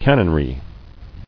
[can·non·ry]